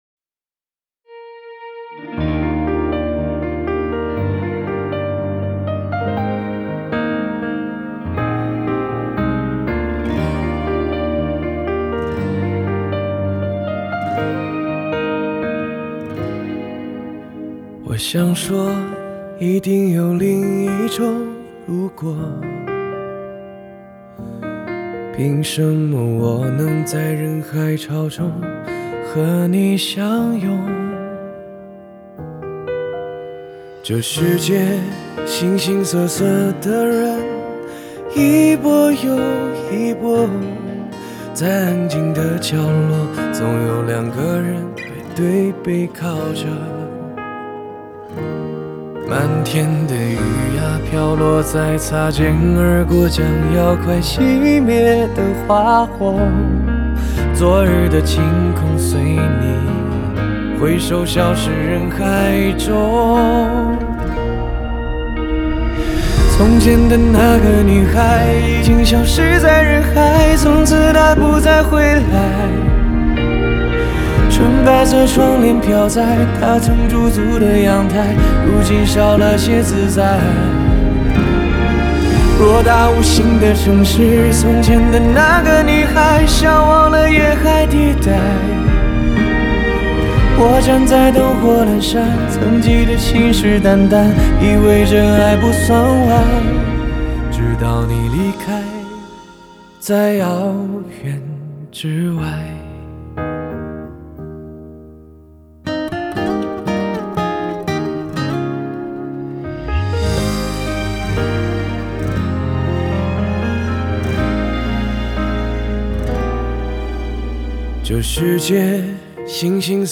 木吉他Acoustic Guitar
电吉他Electric Guitar
和声Backing Vocal